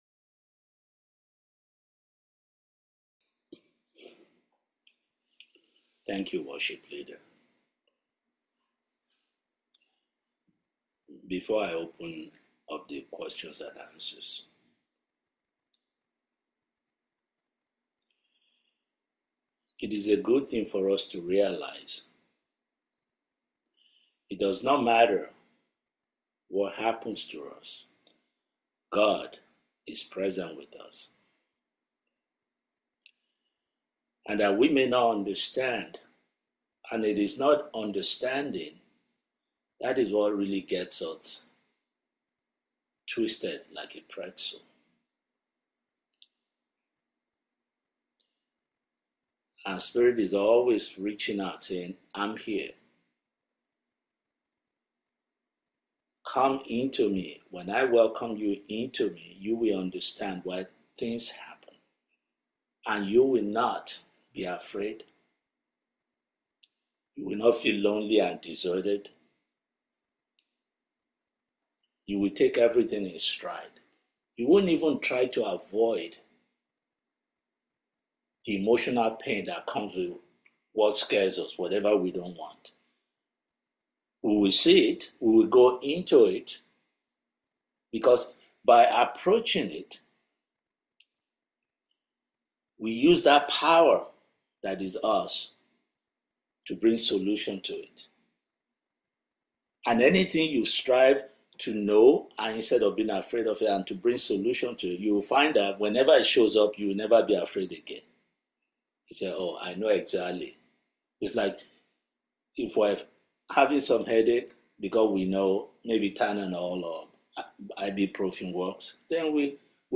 September 2025 Satsang